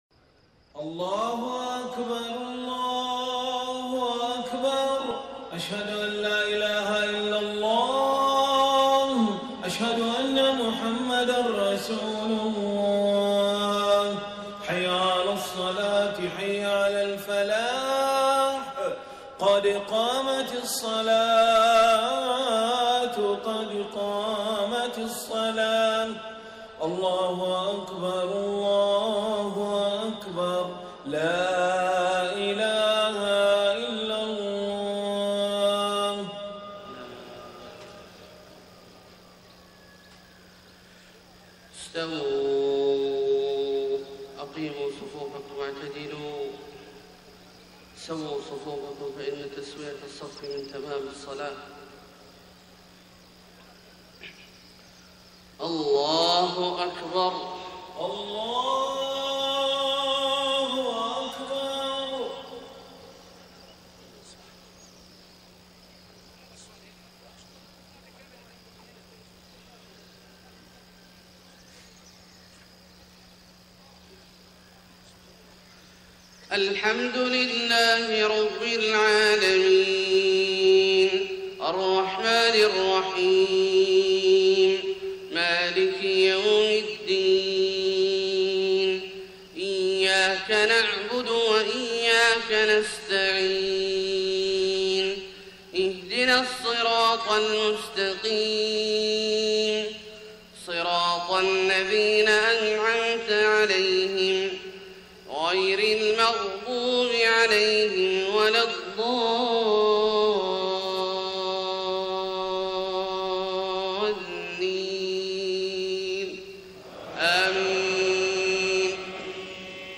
صلاة الفجر 5 ربيع الأول 1431هـ سورتي السجدة و الإنسان > 1431 🕋 > الفروض - تلاوات الحرمين